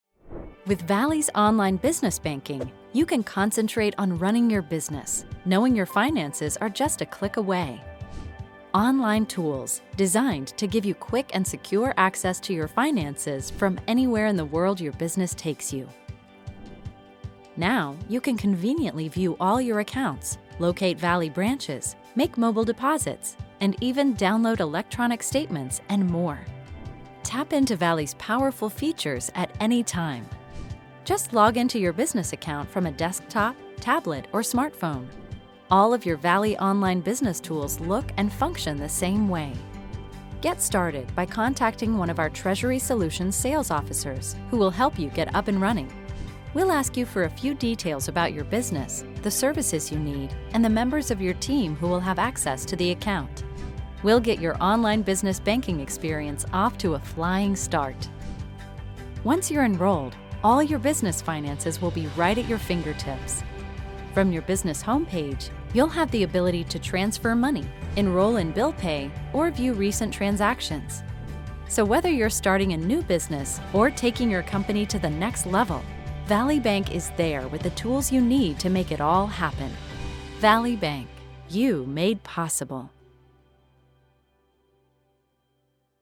standard us
corporate narration